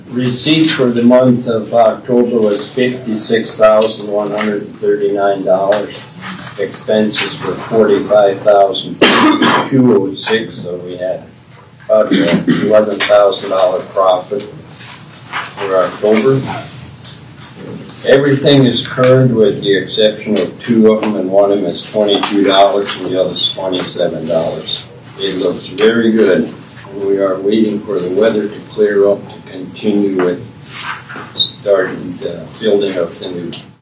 The Walworth County Commission held a regular meeting on Wednesday, Dec. 10th.
Commissioner Duane Mohr gave the Landfill report.